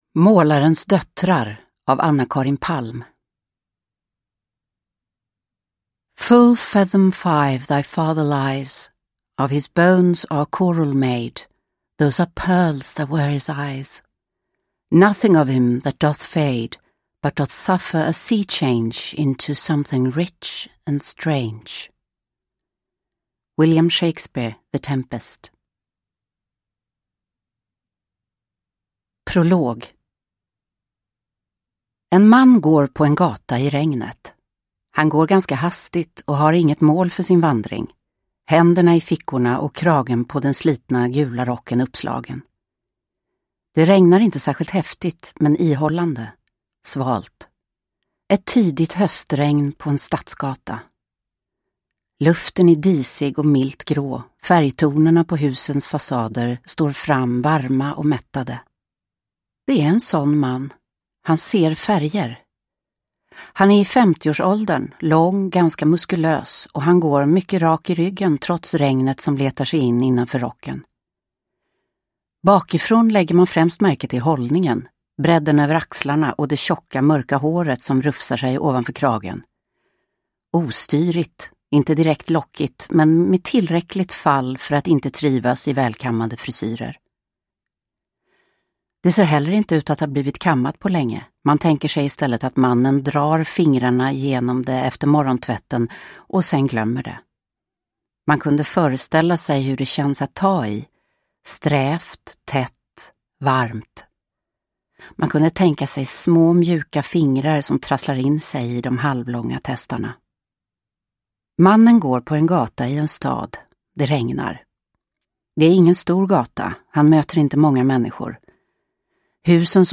Målarens döttrar – Ljudbok – Laddas ner
Uppläsare: Livia Millhagen